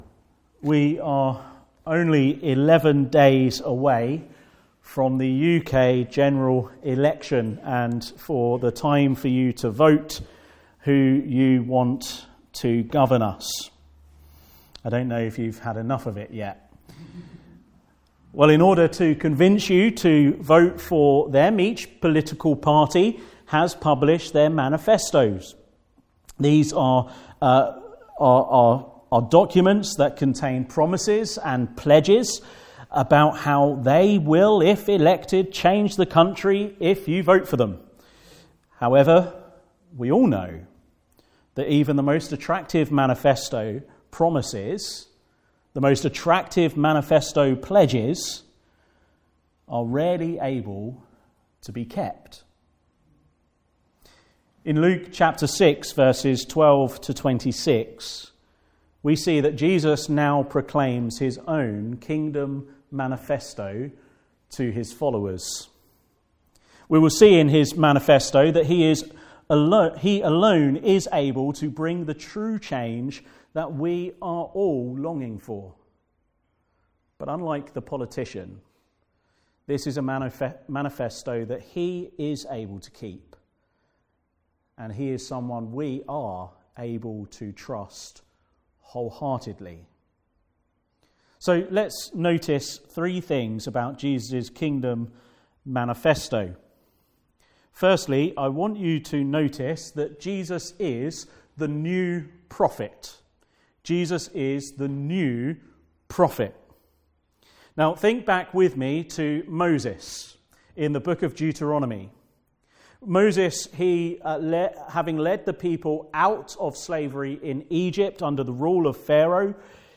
Service Type: Afternoon Service